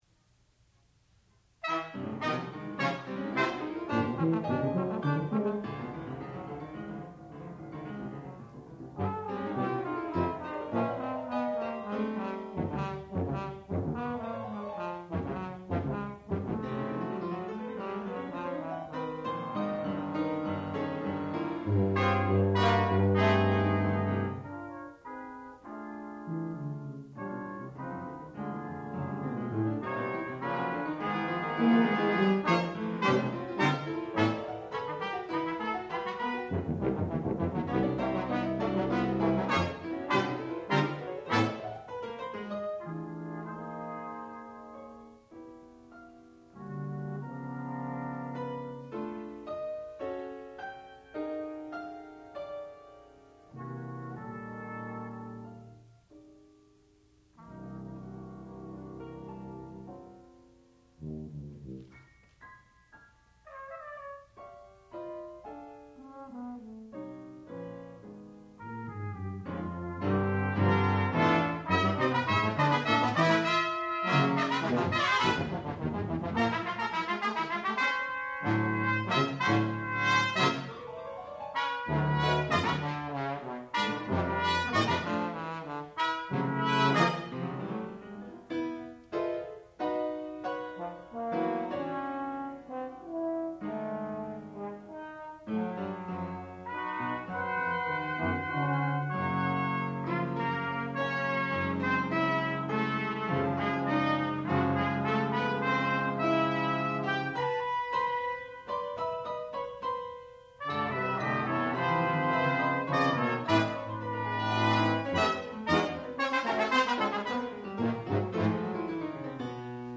sound recording-musical
classical music
Student Recital
piano